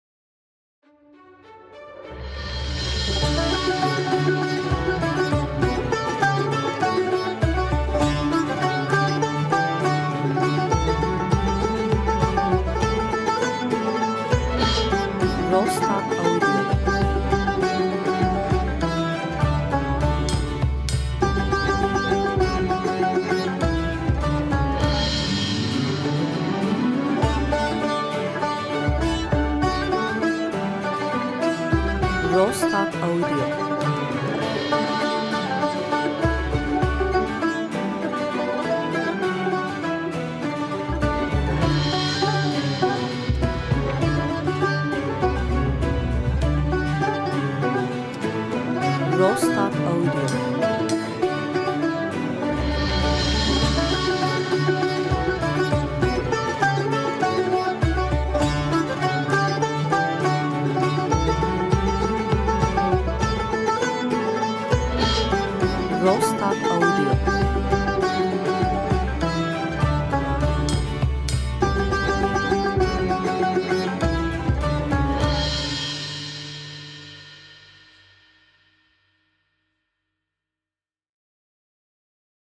enstrümantal hareketli müzik